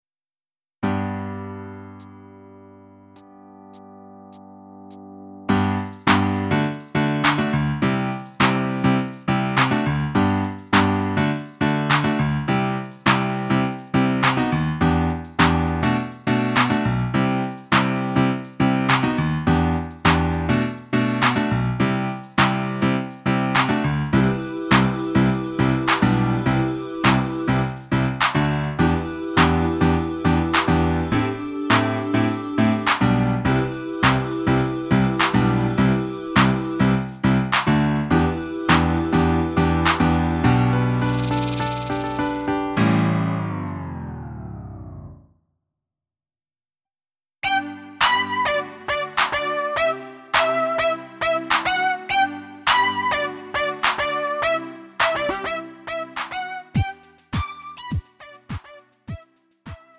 음정 원키 3:14
장르 구분 Lite MR